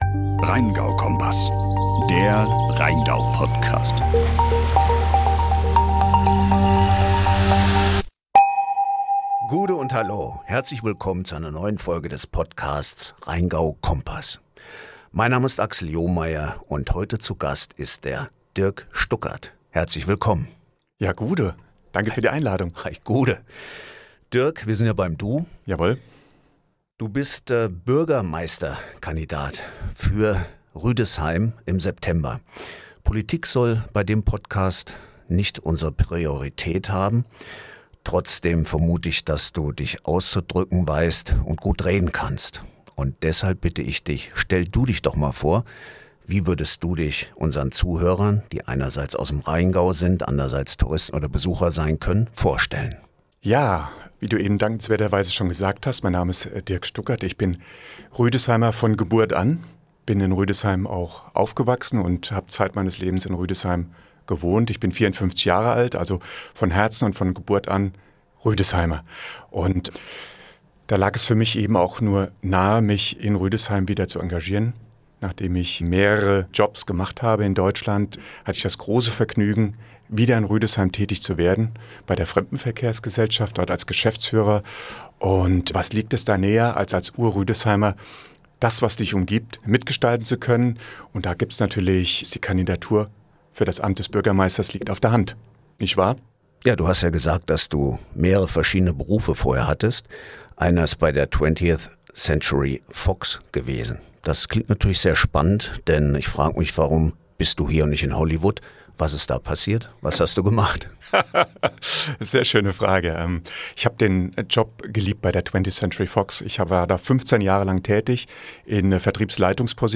Beschreibung vor 7 Monaten In dieser Folge haben wir einen ganz besonderen Gast